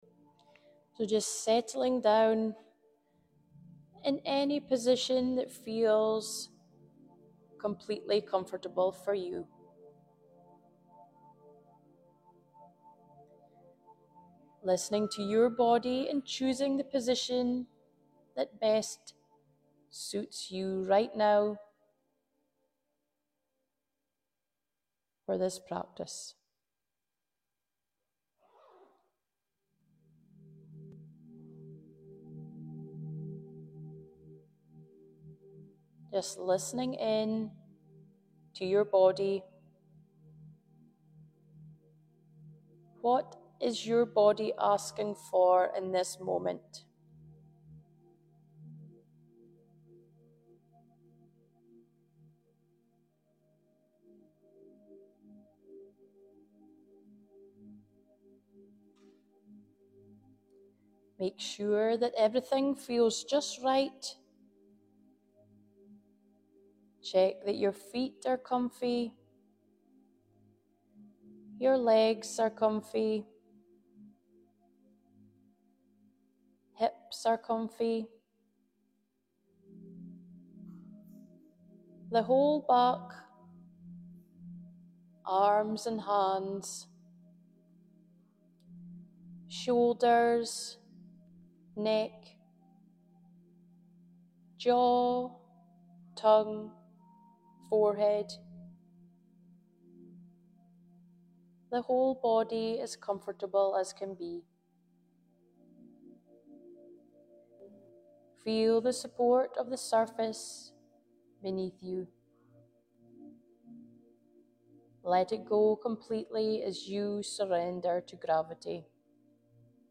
It's time to set everything aside and dedicate just 4 minutes to finding peace and comfort within yourself. Join me as I guide your attention inwards, allowing you to unwind, relax, and find a moment of stillness in the midst of your busy day. Discover the power of these precious minutes for combating anxiety and recharging your inner energy.